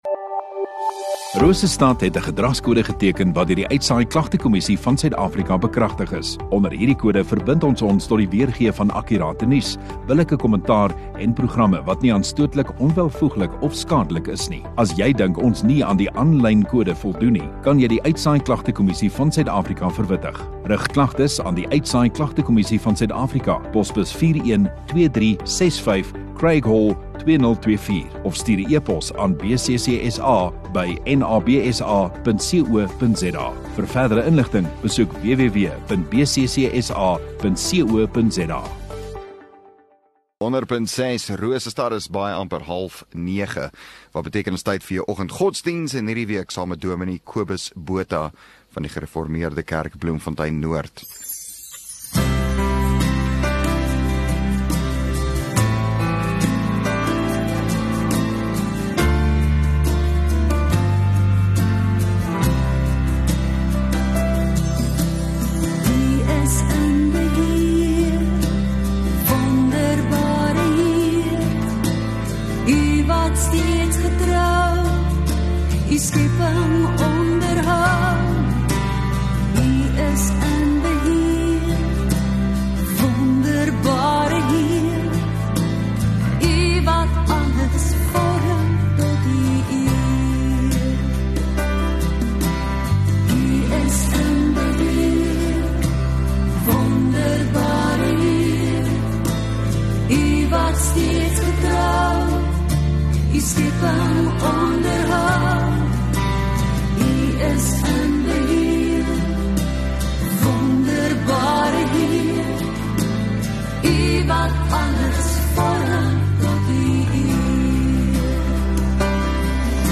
14 May Woensdag Oggenddiens